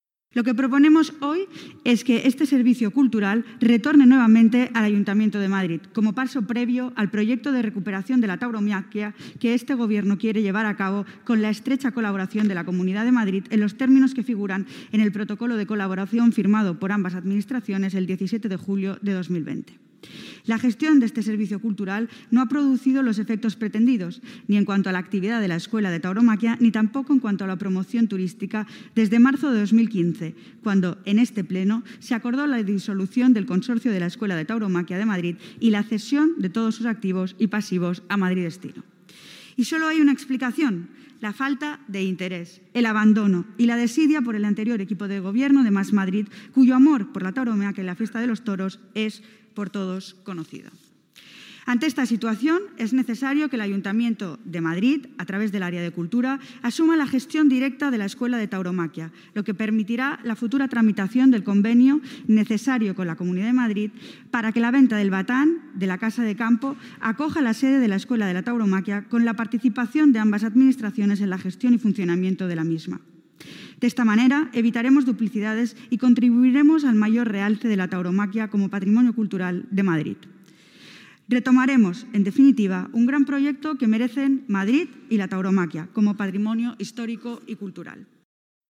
Nueva ventana:Palabras de la delegada de Cultura y Turismo, esta mañana, en la sesión plenaria